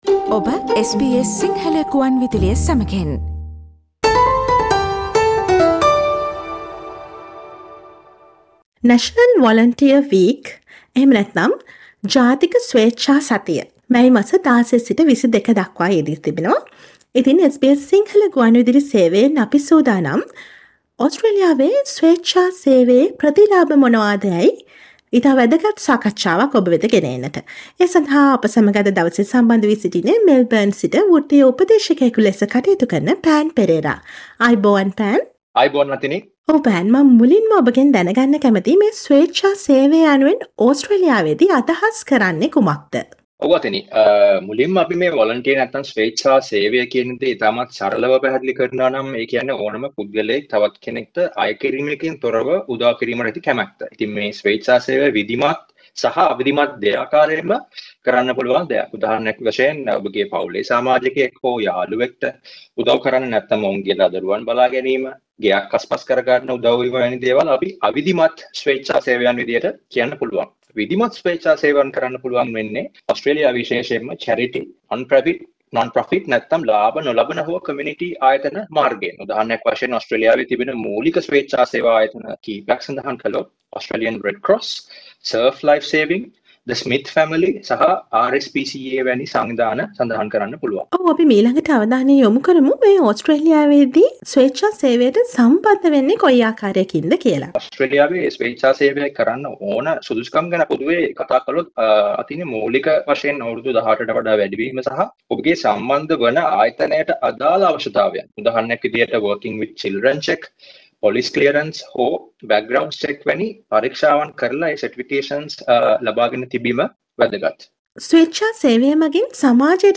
radio discussion